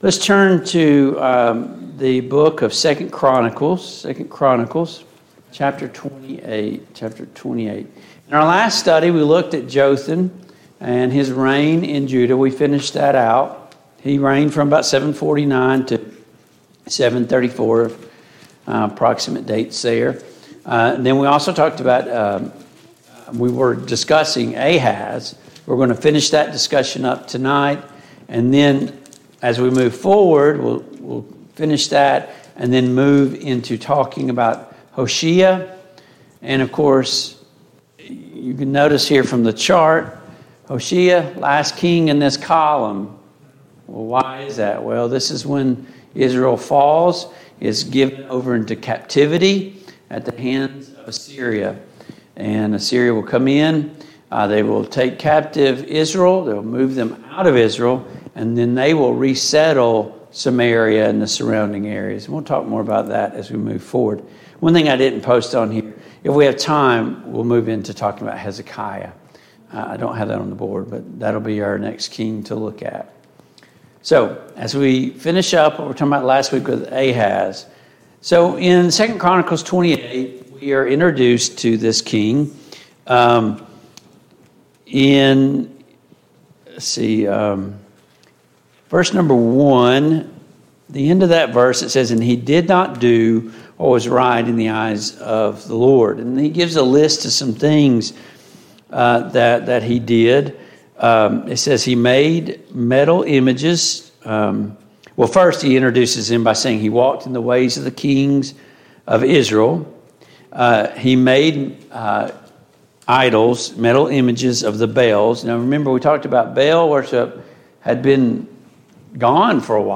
Mid-Week Bible Study